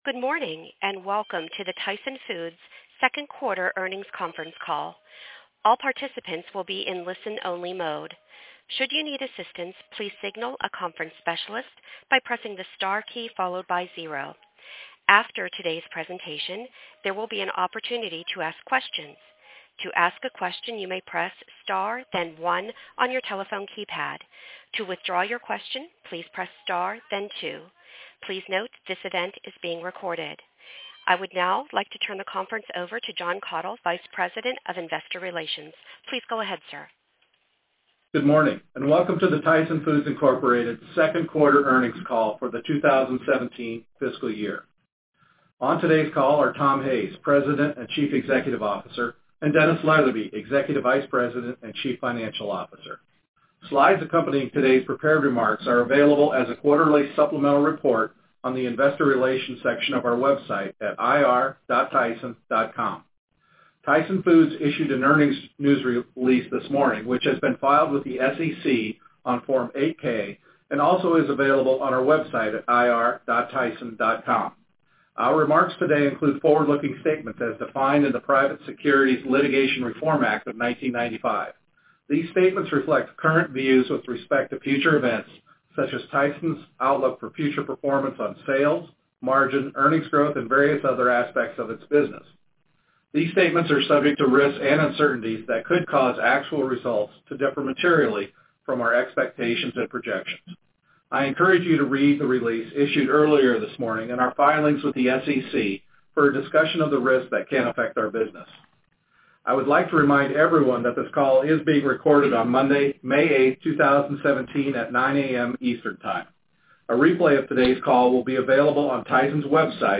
Tyson Foods Inc. - Q2 2017 Tyson Foods Earnings Conference Call